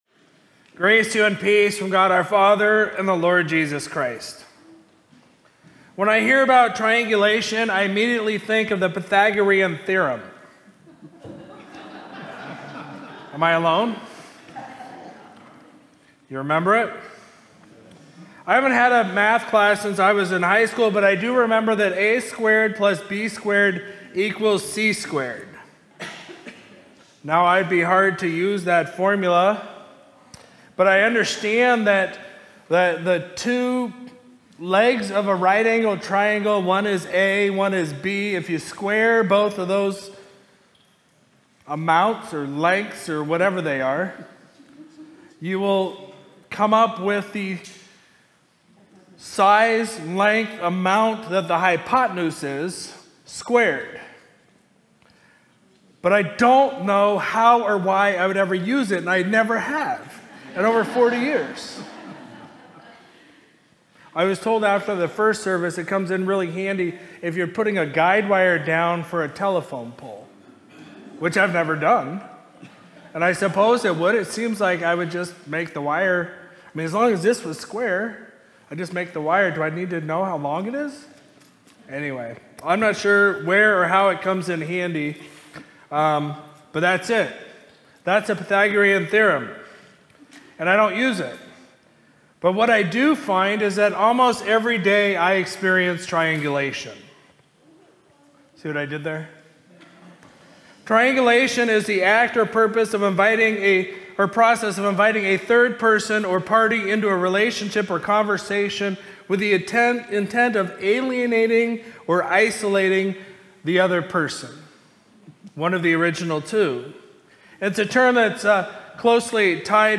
Sermon from Sunday, July 20, 2025